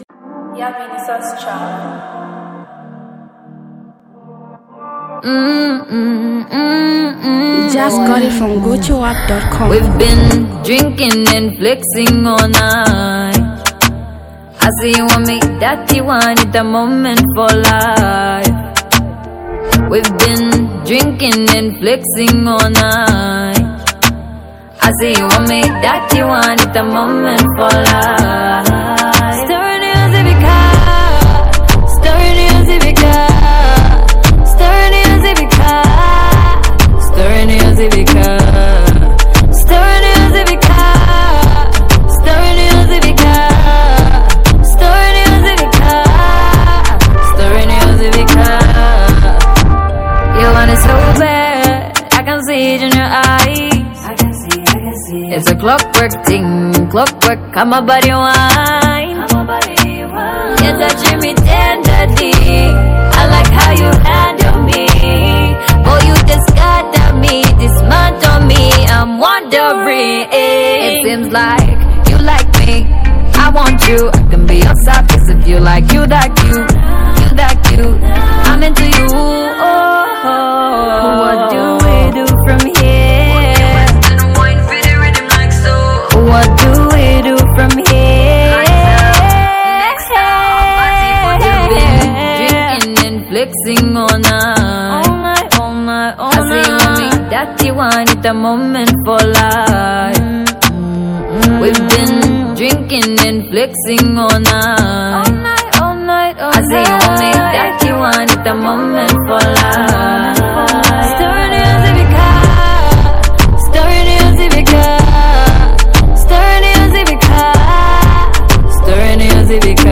Zambian prominent female singer and songwriter artist
heartfelt rhyme